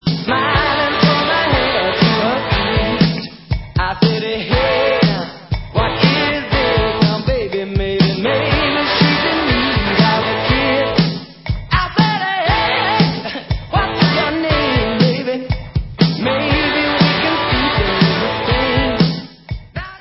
A COLLECTION OF FEEL-GOOD ANTHEMS OF SUMMER